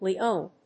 /liˈon(米国英語), li:ˈəʊn(英国英語)/